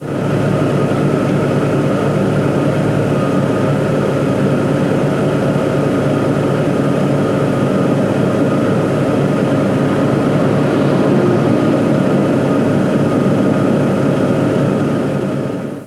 Coche Golf al ralentí
coche
motor
Sonidos: Transportes